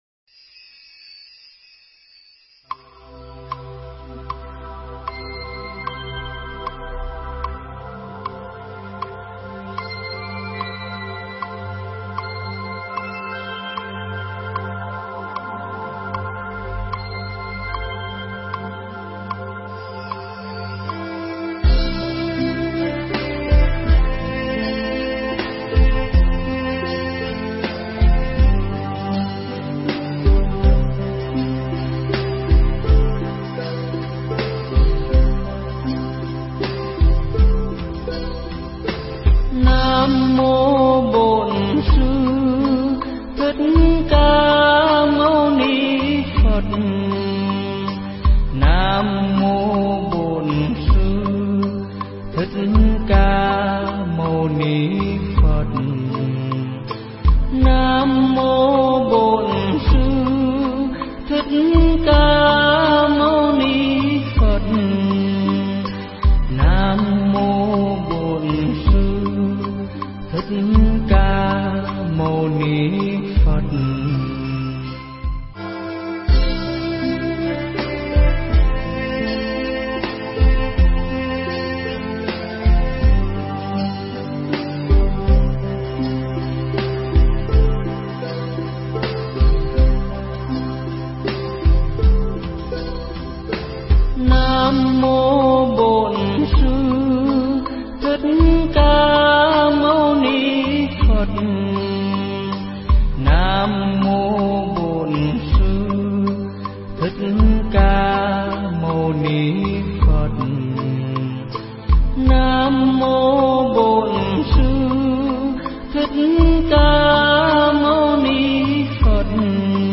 Nghe Mp3 thuyết pháp Sức Mạnh Của Đồng Tiền